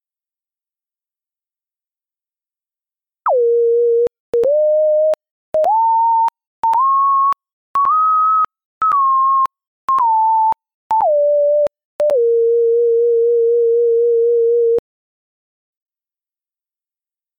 En cada unha das preguntas atoparedes un arquivo de audio que contén unha secuencia de son, é dicir, unha onda que vai subindo ou baixando de maneira concreta en cada caso.